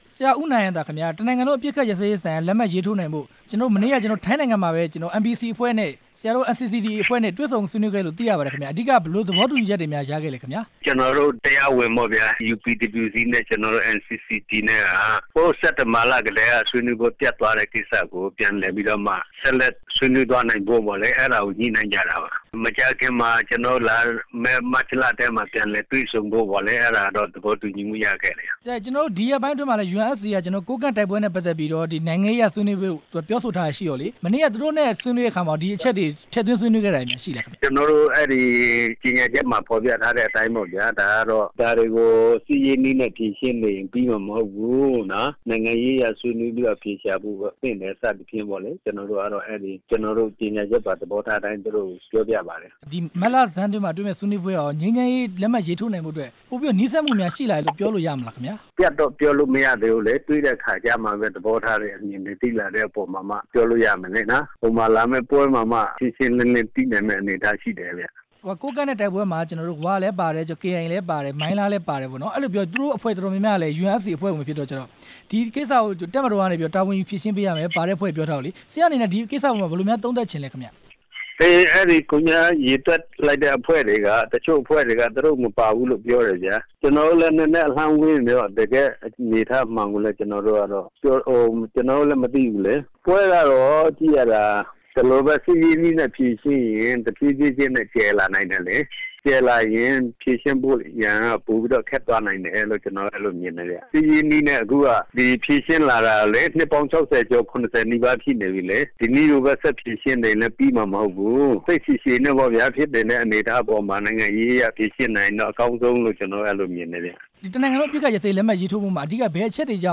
ငြိမ်းချမ်းရေးဆွေးနွေးပွဲကို မတ်လထဲမှာ ပြန်စတင်တော့မယ့်အကြောင်း မေးမြန်းချက်